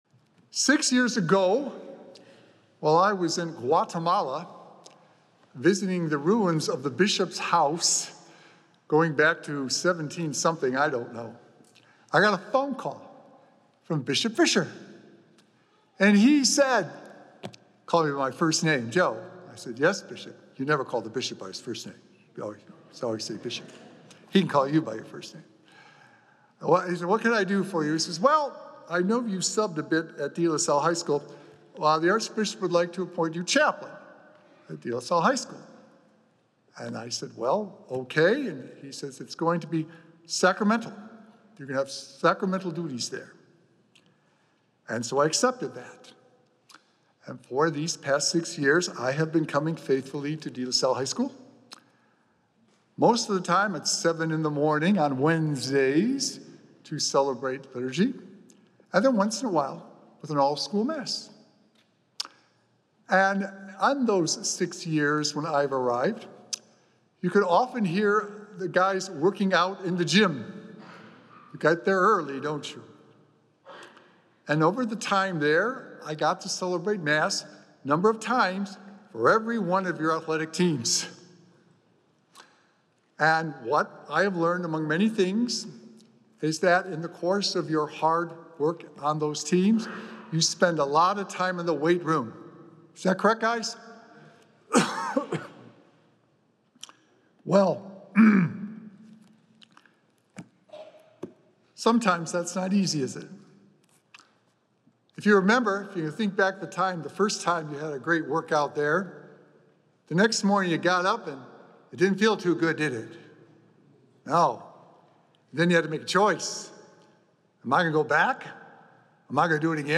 Recorded Live on Saturday, April 19th, 2025 at St. Malachy Catholic Church.